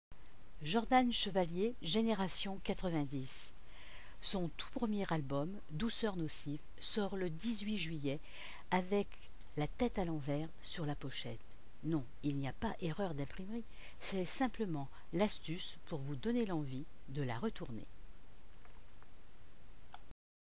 un jeune chanteur